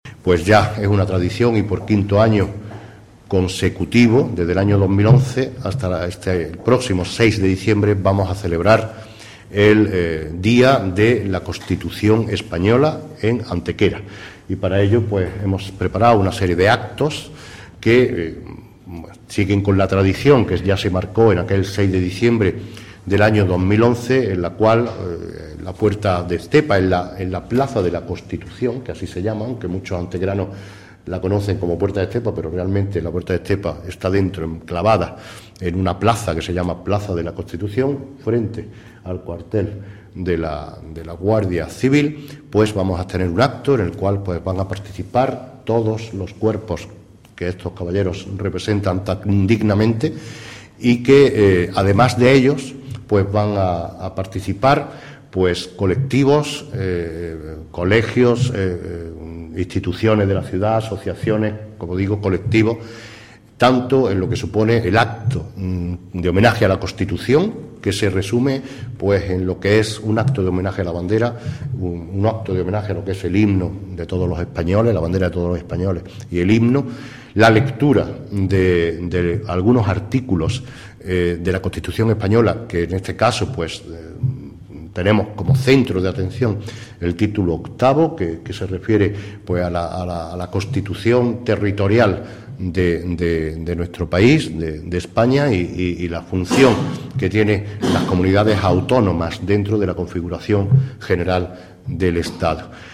En la rueda de prensa de presentación también han estado presentes los respectivos responsables locales del Ejército del Aire, Guardia Civil, Cuerpo Nacional de Policía, Policía Local, Consorcio Provincial de Bomberos y Protección Civil.
Cortes de voz